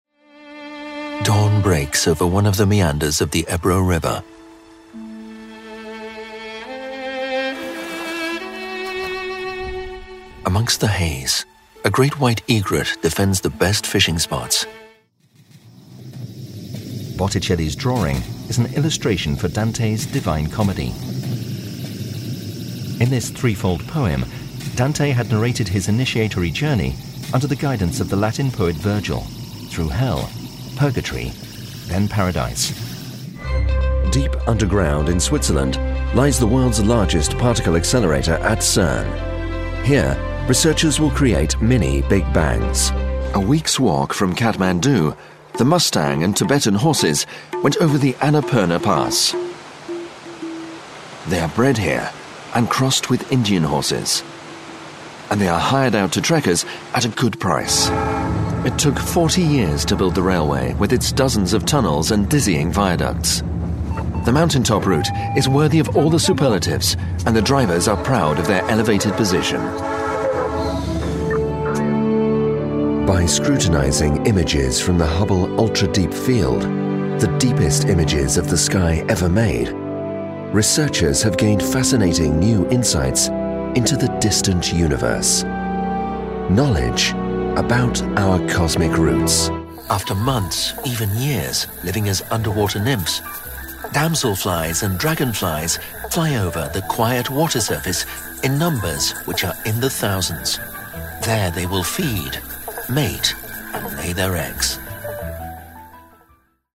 Warm, rich & reassuring British voice, at once approachable and authoritative.
Sprechprobe: Sonstiges (Muttersprache):